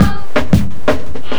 JUNGLE3-R.wav